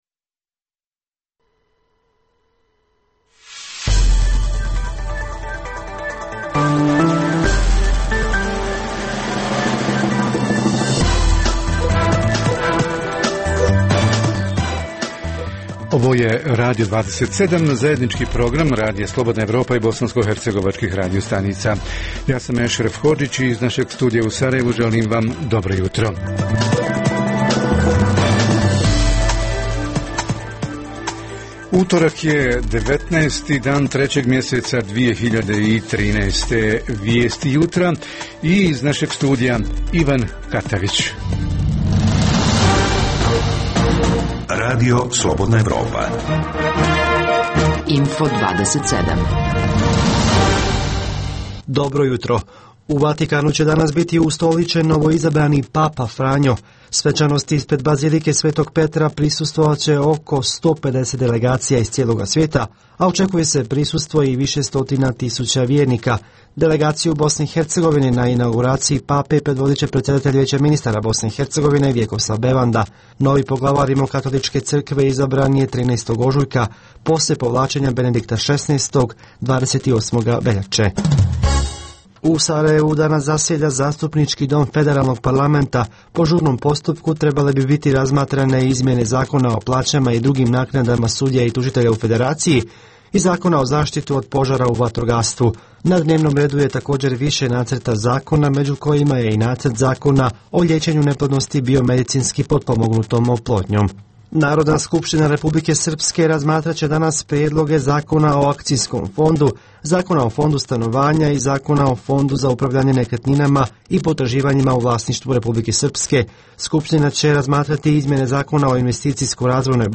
- Središnja tema ovog jutra: 100 dana lokalne vlasti – šta su obećali, šta jesu, a šta i zašto nisu uradili načelnici i njihovi timovi izvršne lokalne vlasti? O tome će naši reporteri iz Doboja, Jablanice, Prijedora i Brčko-distriktu BiH.